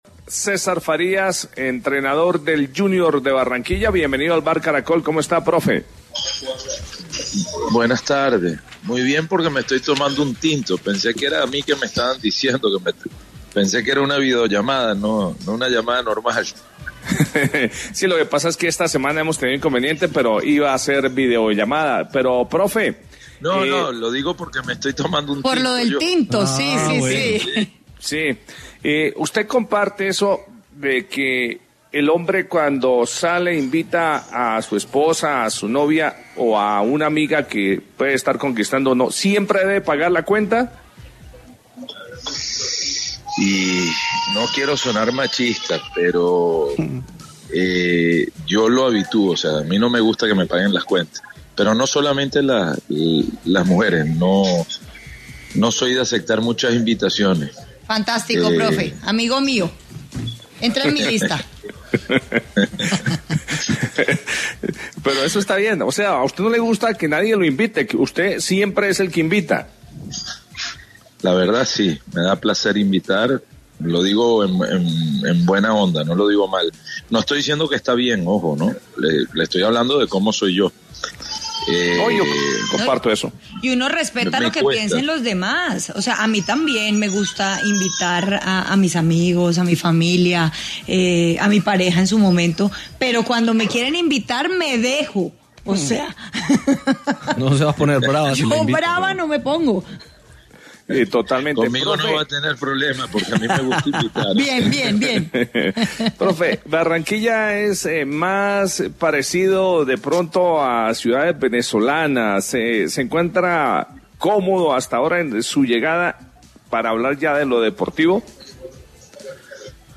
César Farías, entrevista El Vbar | 4 octubre 2024